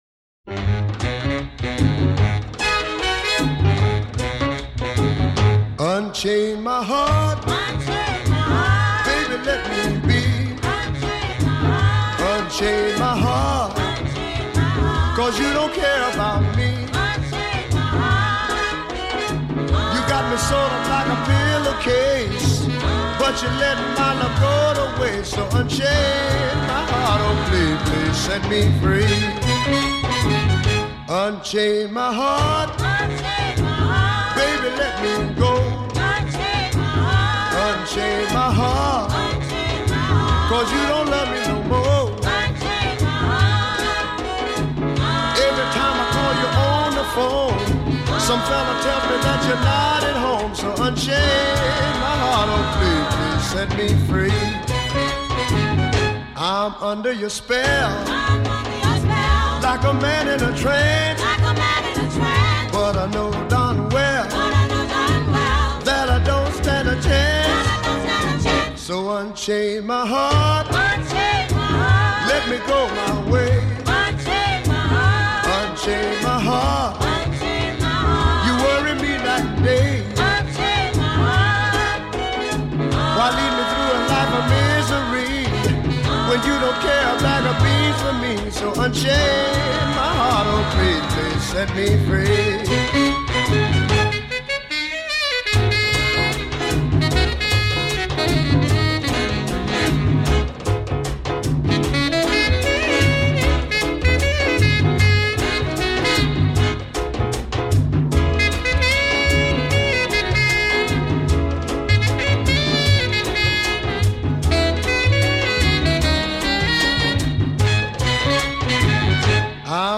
Soul Music 靈魂音樂
我個人喜歡他那種沙啞的歌聲